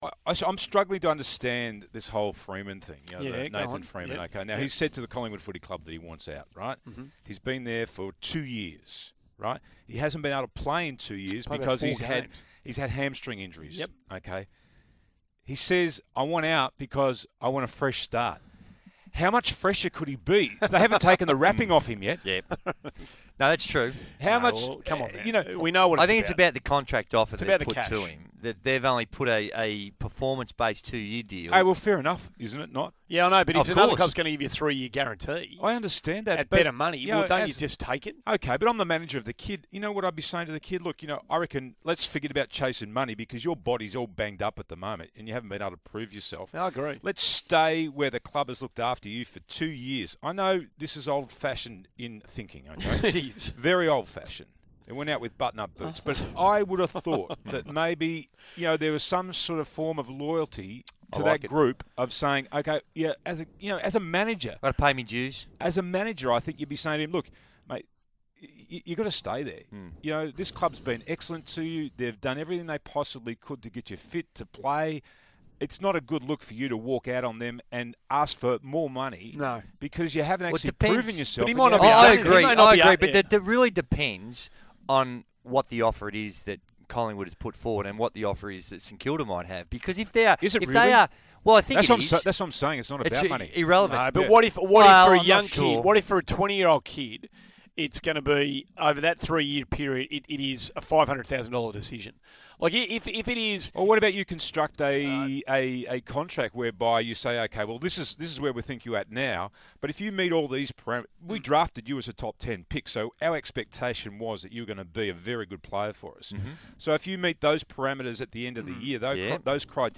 speak with the Morning Glory team on SEN 1116 on 24 September 2015.